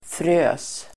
Ladda ner uttalet
Uttal: [frö:s]